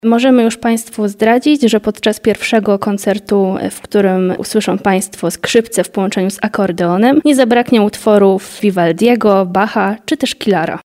skrzypce_akordeon_0205_ns.mp3